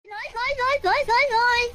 Nice Nice Nice - Short - Bouton sonore